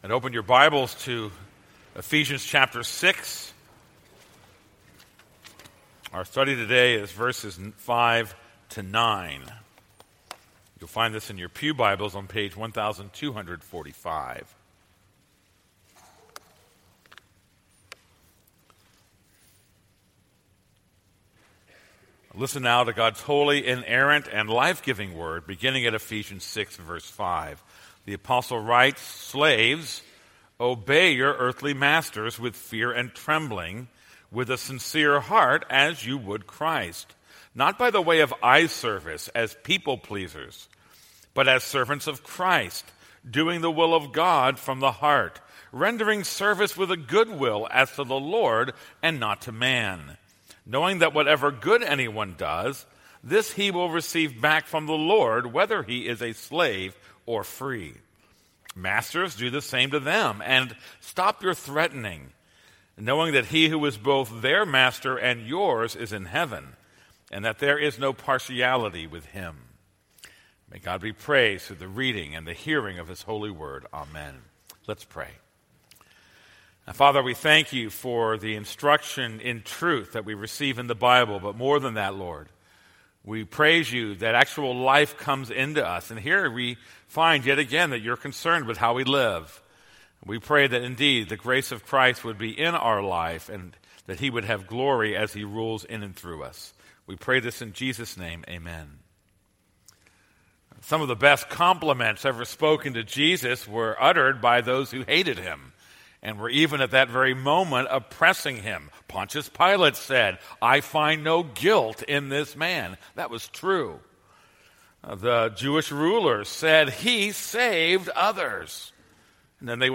This is a sermon on Ephesians 6:5-9.